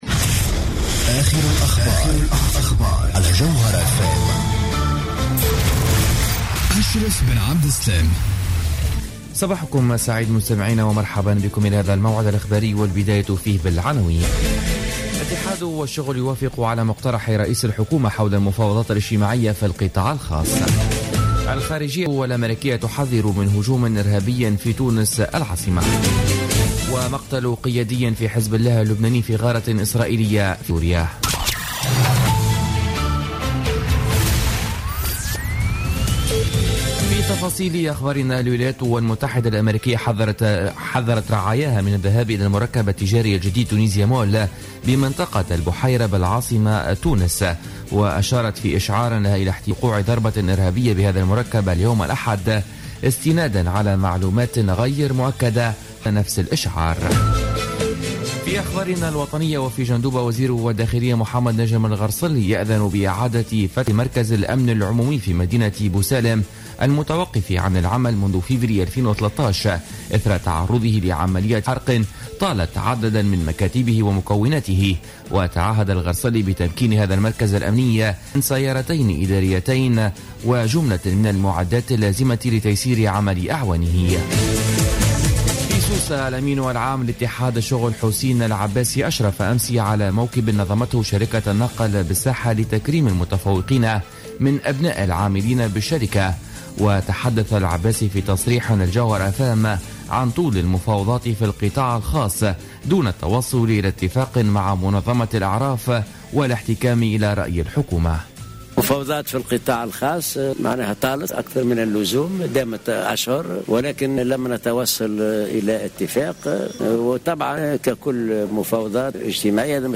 نشرة أخبار السابعة صباحا ليوم الأحد 20 ديسمبر 2015